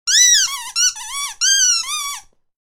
Playful Dog Toy Squeak Sound Effect
Description: Playful dog toy squeak sound effect. This playful squeaky toy sound captures a dog’s excitement and energy. It creates a humorous and fun atmosphere perfect for videos, games, or animations.
Playful-dog-toy-squeak-sound-effect.mp3